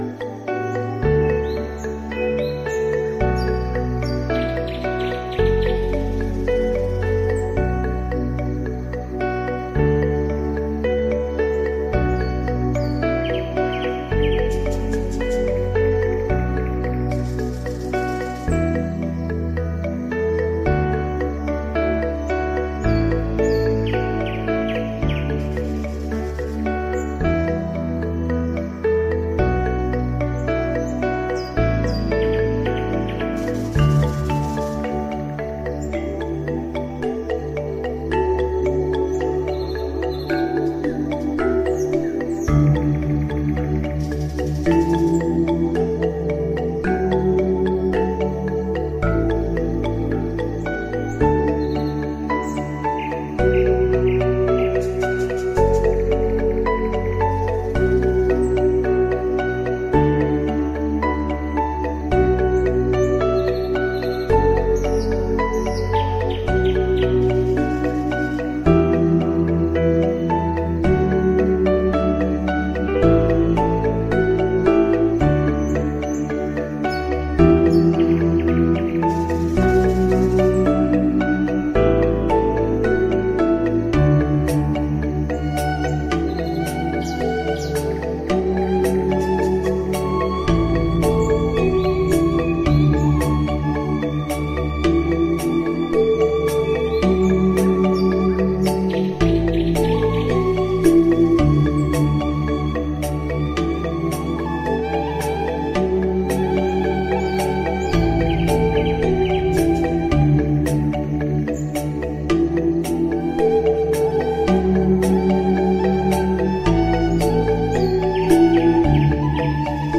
RATU-ANOM-BALI-RELAXING-SONG-.Cut_-OK.mp3